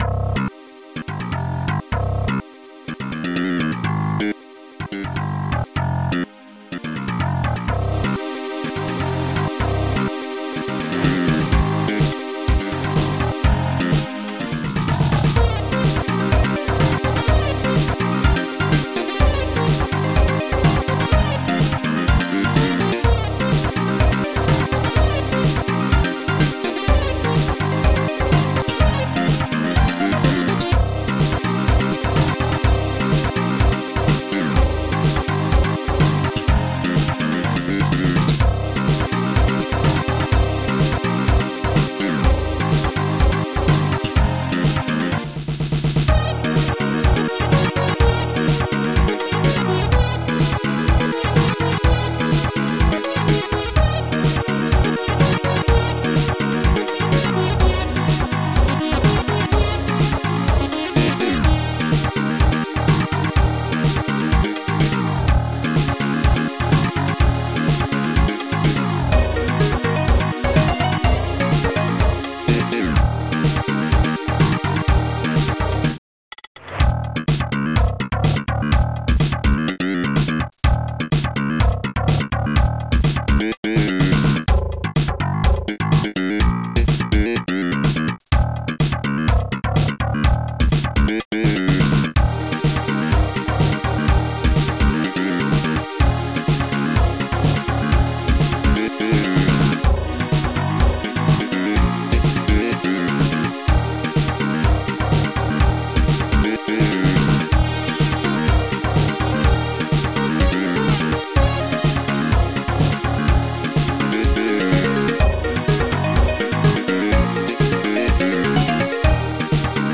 Protracker Module
2 channels
bass attack